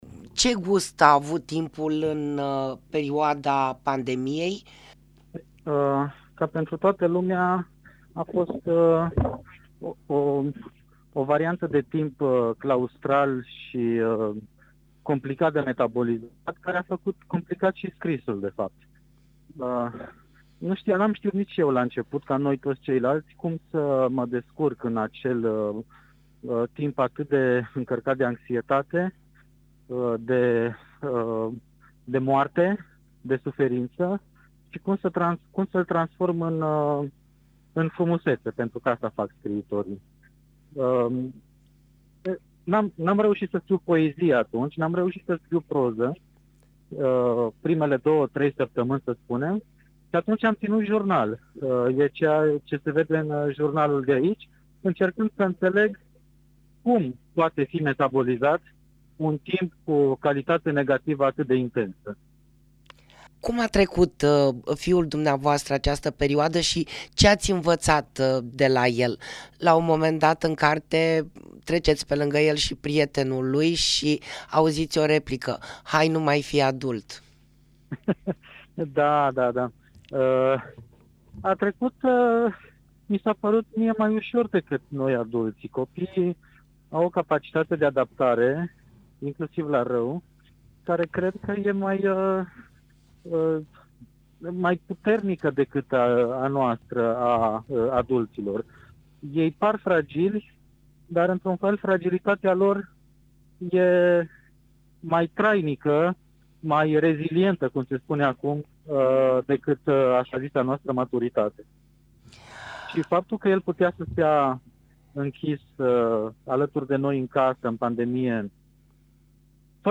Asculta Live